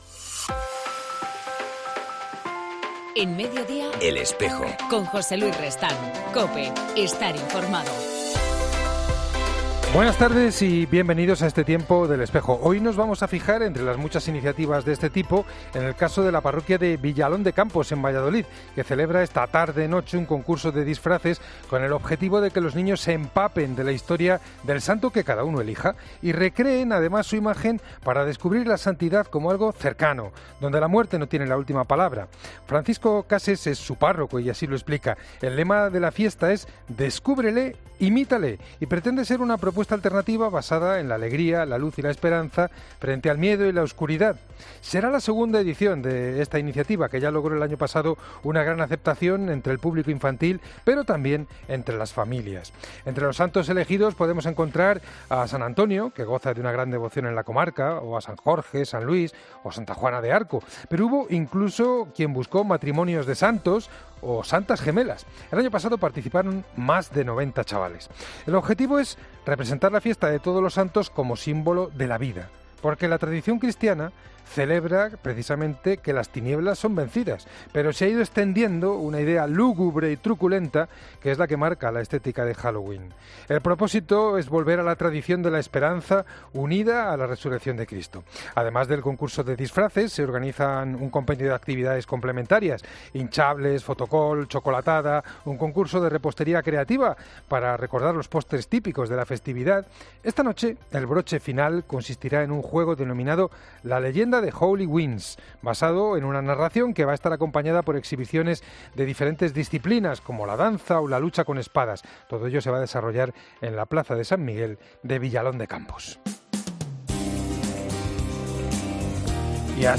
En El Espejo del 31 de octubre entrevistamos a Juan Antonio Martínez Camino, obispo auxiliar de la Archidiócesis de Madrid